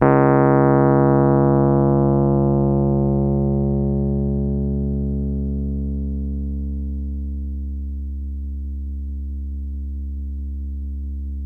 RHODES CL03L.wav